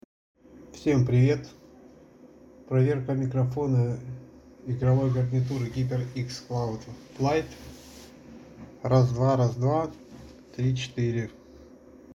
Микрофон у HyperX Cloud Flight весьма качественный с мощным шумоподавлением.
hyperx-cloud-flight-mikrofon.mp3